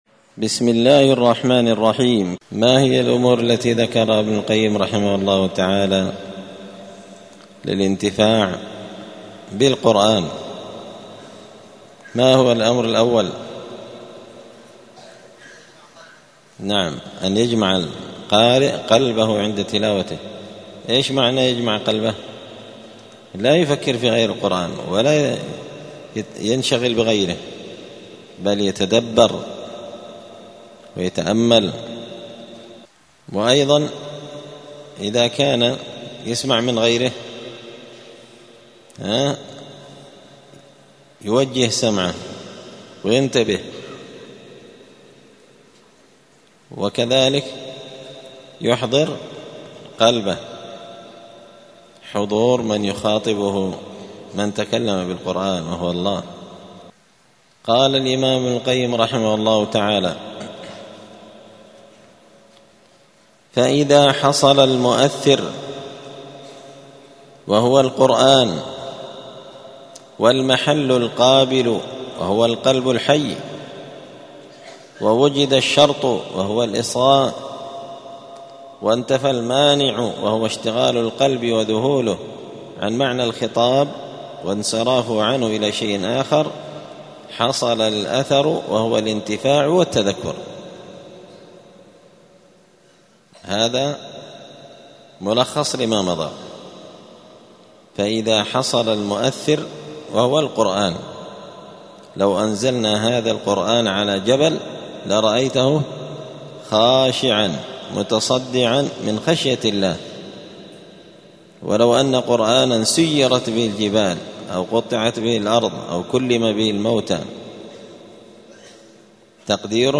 الجمعة 1 ربيع الثاني 1446 هــــ | الدروس، دروس الآداب، كتاب الفوائد للإمام ابن القيم رحمه الله | شارك بتعليقك | 23 المشاهدات